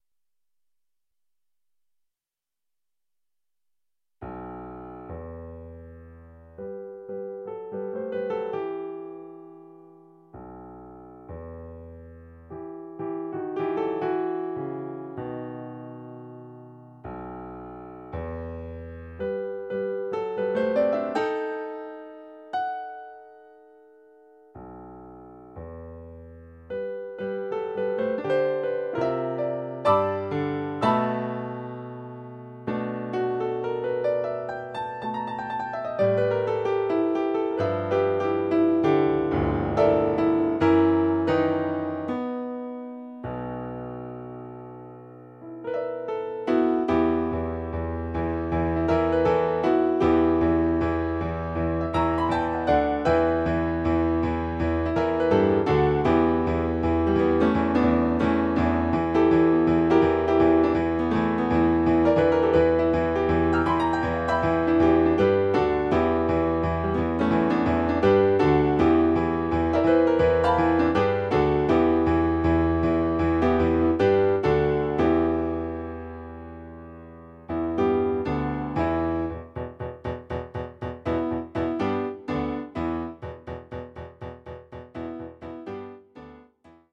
locomotive.mp3